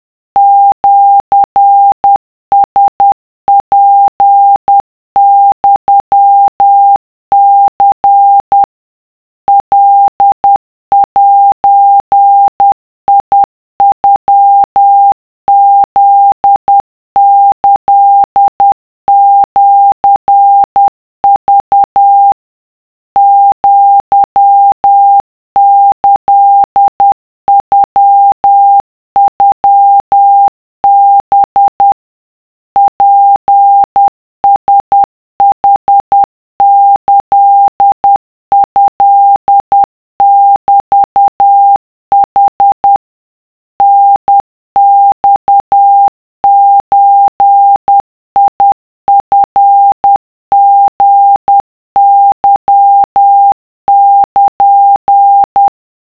【cw】2【wav】 / 〓古文で和文系〓
とりあえず50CPM (=10WPM)で作ってみた